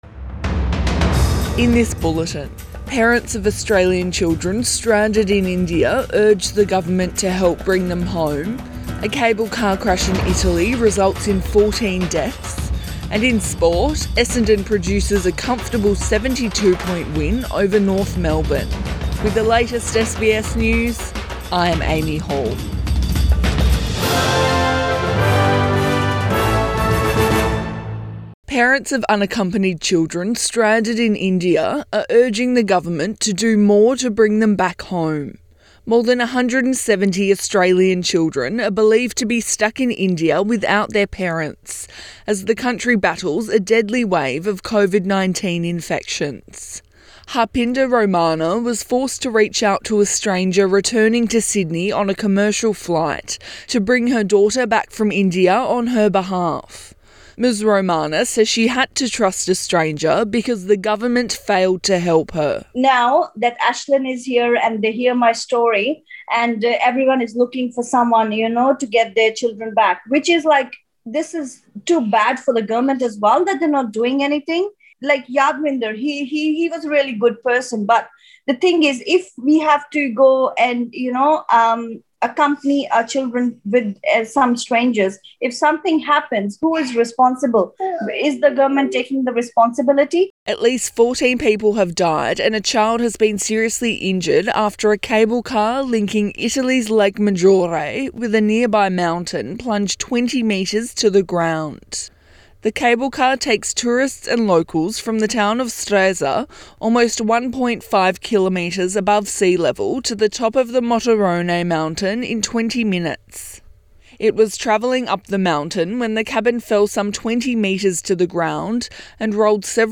AM bulletin 24 May 2021